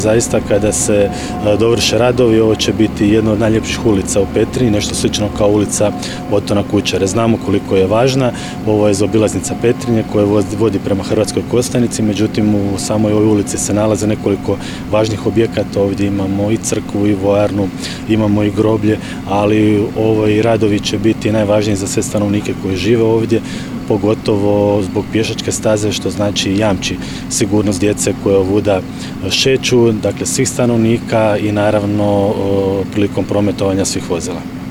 Očekujemo završetak radova početkom svibnja, izjavio je, prilikom obilaska, župan Ivan Celjak